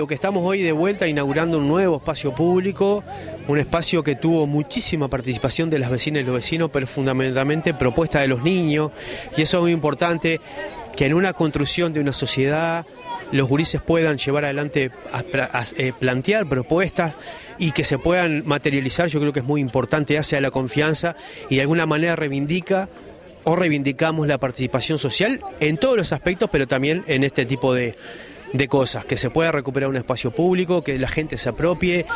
marcelo_metediera_-_intendente_interino_de_canelones.mp3